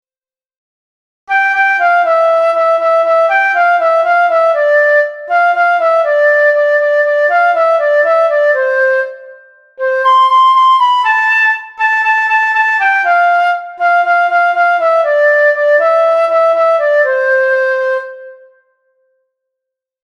Música
Cancion_pitino_flauta.mp3